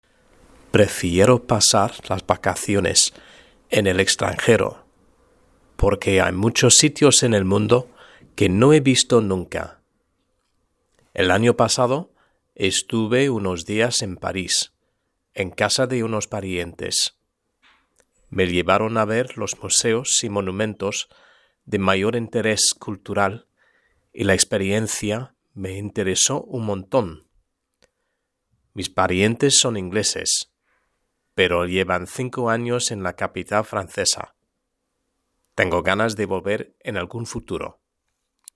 Lectura en voz alta: 3.1 – Los viajes y el turismo (H)